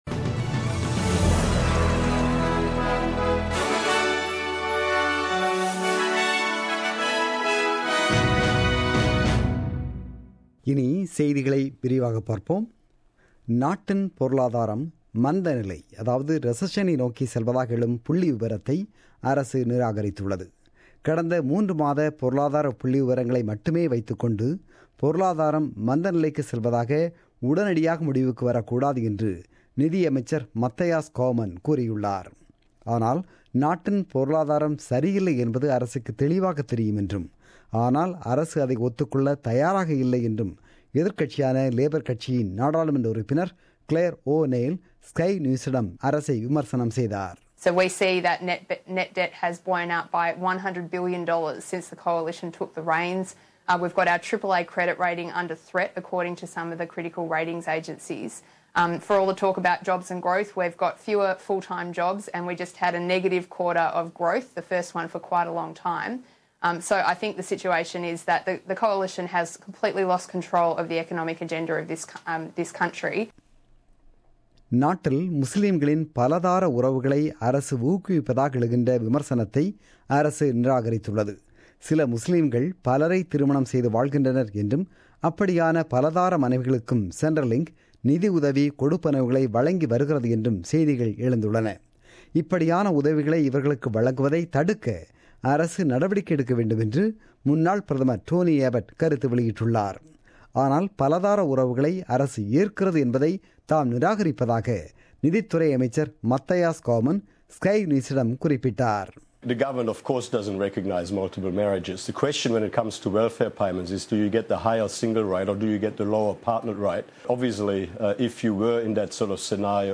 The news bulletin broadcasted on 11 December 2016 at 8pm.